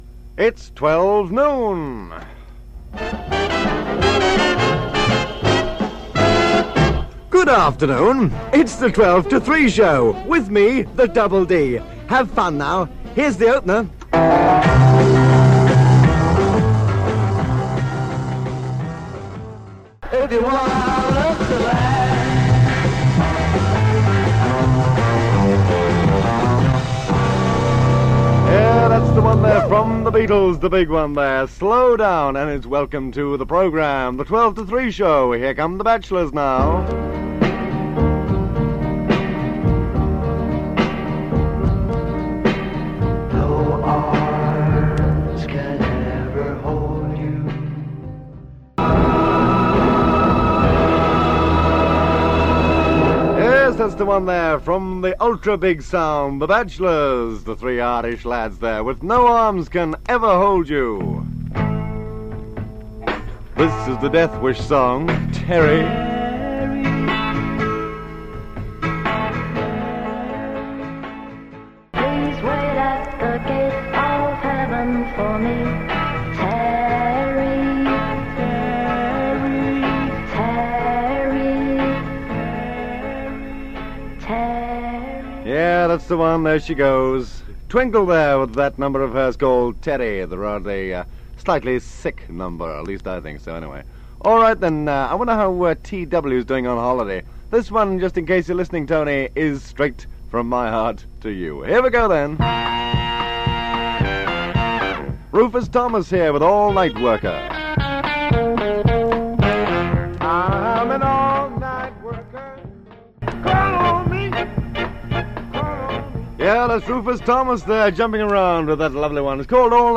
Radio Caroline South and Radio London's ships were anchored just off the coast of Clacton so the audio quality of the recordings from these stations is superb.